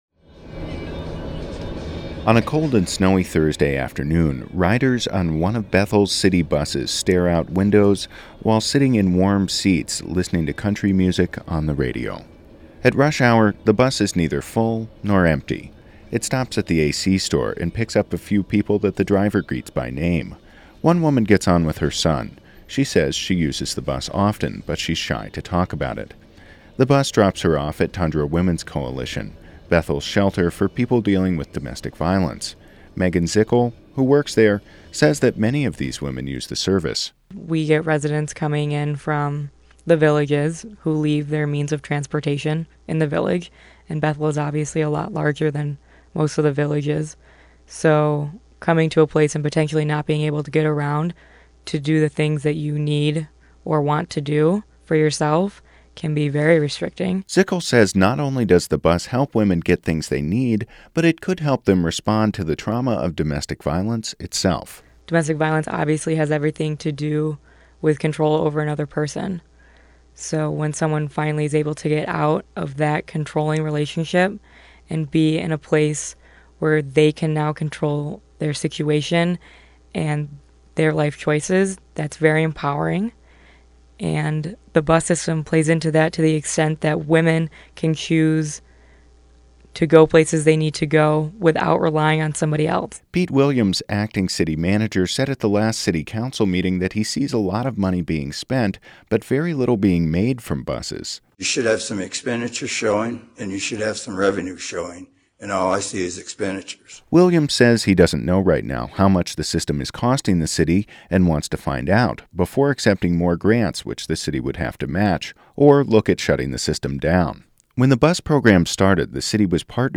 On a cold and snowy Thursday afternoon, riders on one of Bethel’s city buses stare out the windows while sitting in warm seats and listening to country music on the radio.